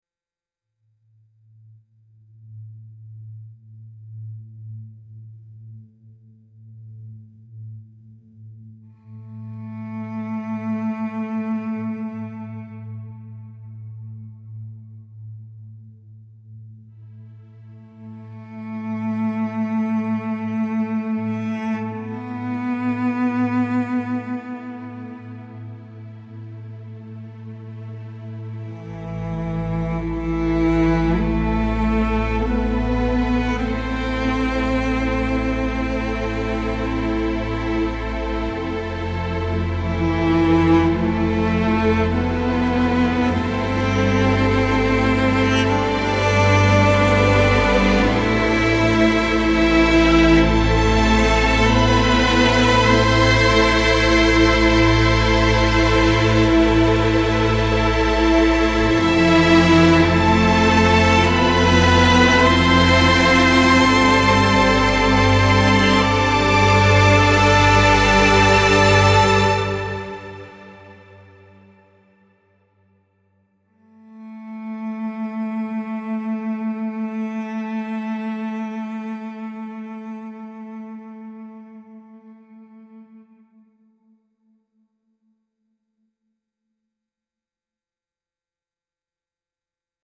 dramatic slow-motion cinematic music with deep cello and soaring violins